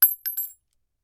Bullet Shell Sounds
bullet_shells
rifle_generic_3.ogg